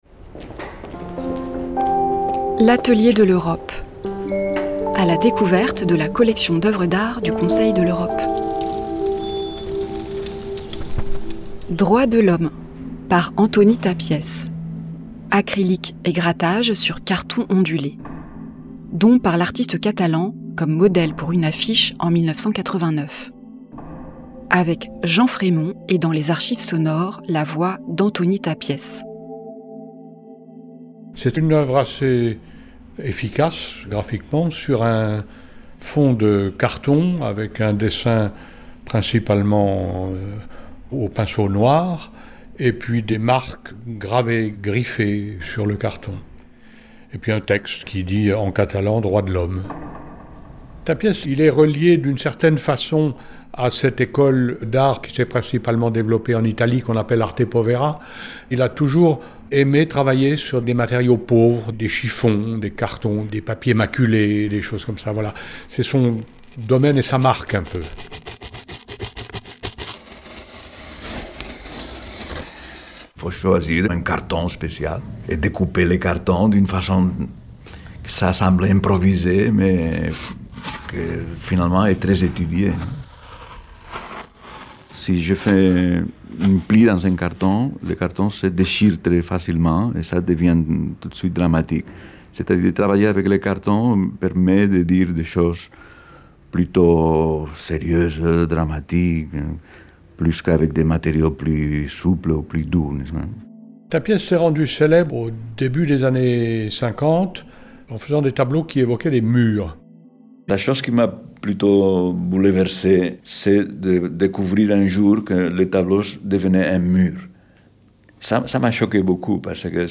Archives sonores : Antoni Tàpies